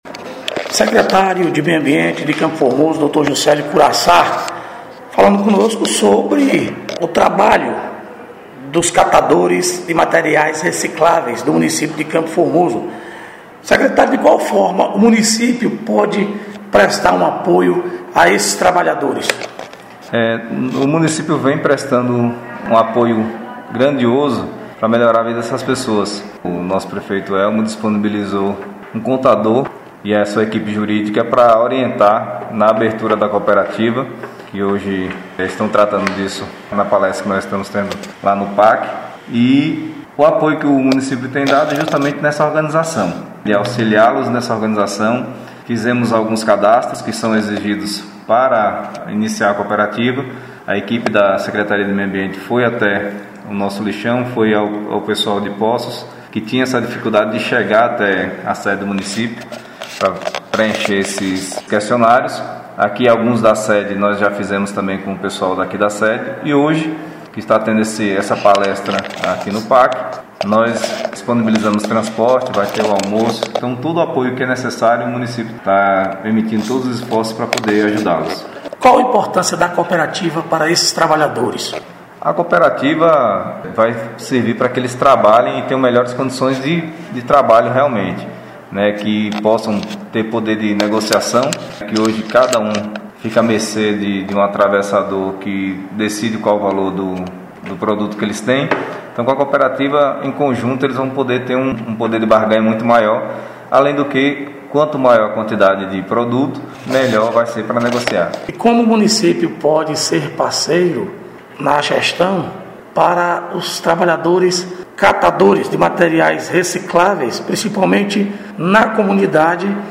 Secretário de Meio Ambiente Jucélio Curaçá – Encontro com Catadores de lixo em CFormoso